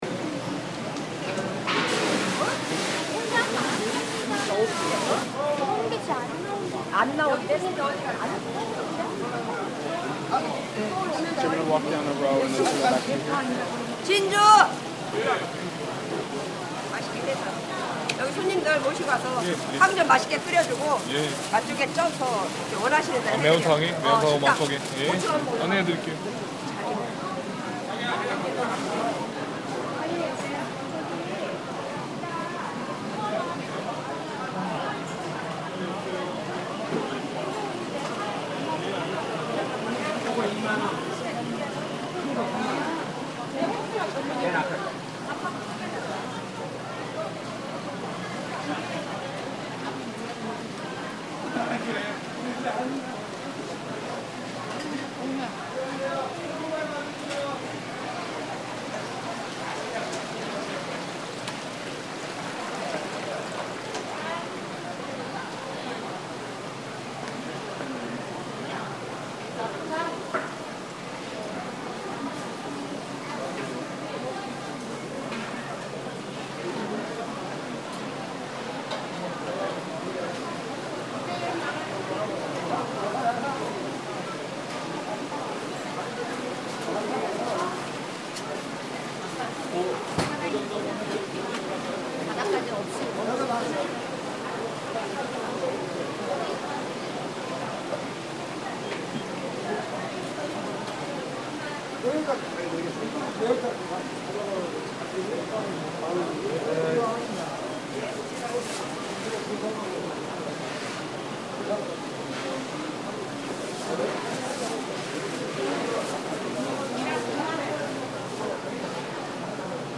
Walking through the Noryangjin Fish Market
South of the river, the Noryangjin Market is entirely devoted to seafood. This is the sound of walking through the Noryangjin Fish Market.